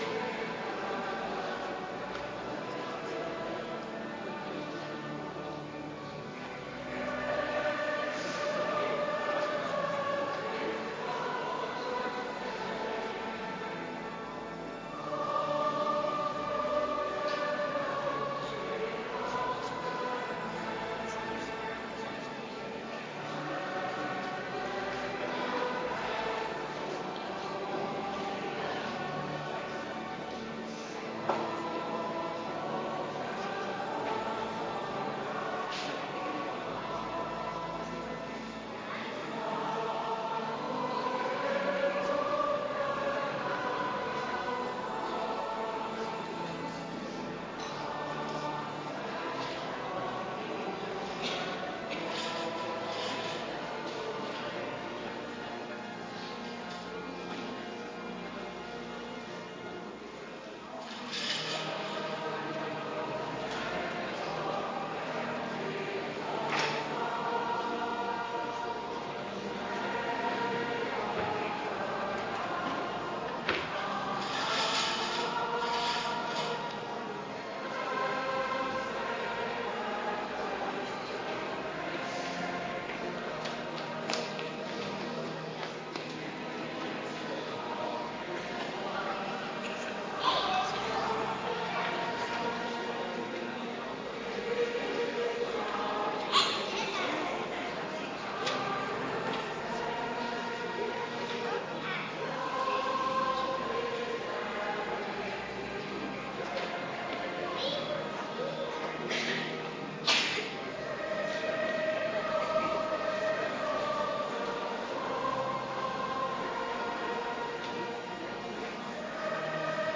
Adventkerk Zondag week 53